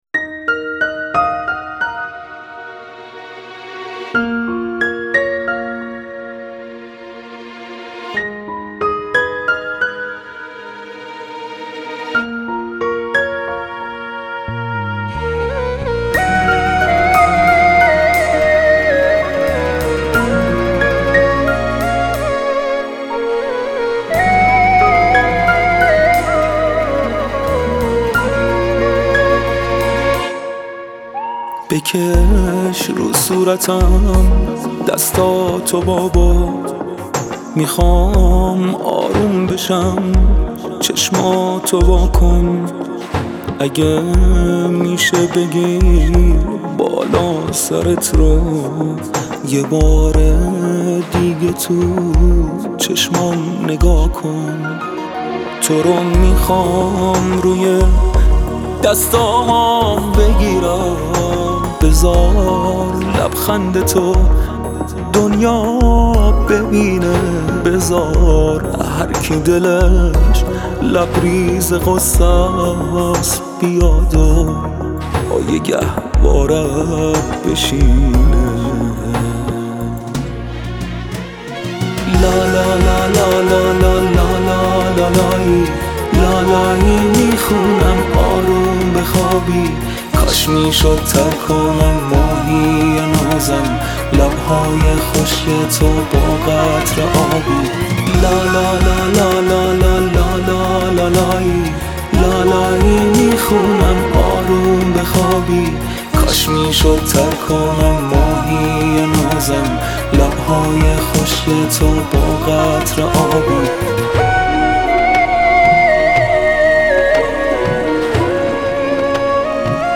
موسیقی جدیدی با فضای عاشورایی